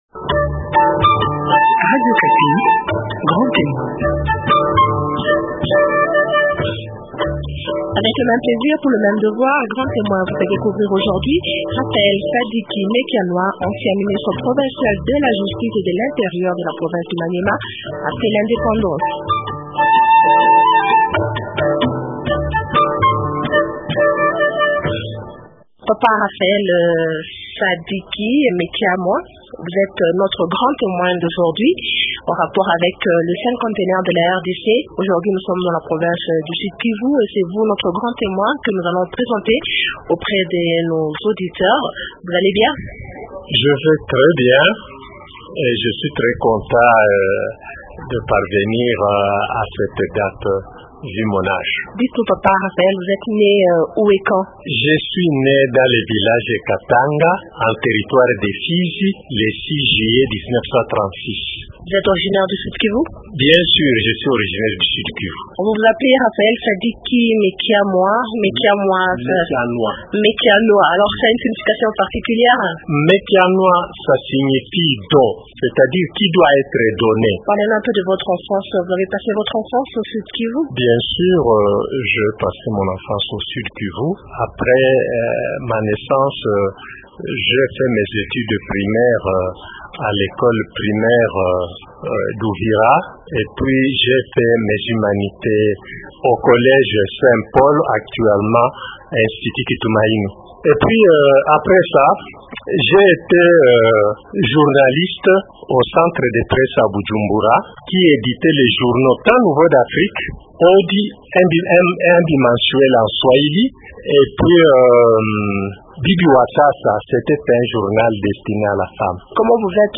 Découvrez aussi dans cet entretien le bilan qu’il dresse du parcours de la RDC, 50 ans après son accession à la souveraineté nationale.